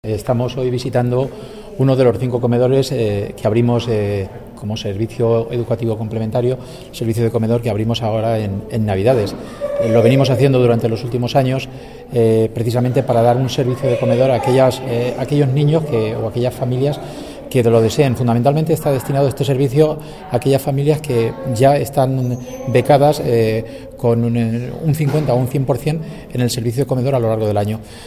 El director provincial de Educación, Cultura y Deporte, Diego Pérez ha explicado que la provincia de Albacete cuenta con cinco comedores escolares abiertos en las fiestas Navideñas, - todos en la ciudad de Albacete-, “un servicio que se ofrece en los últimos años con el propósito de ayudas a aquellos niños y familias que lo deseen, fundamentalmente para alumnos becados con un 50 por ciento, o un cien por cien, durante el resto del año”.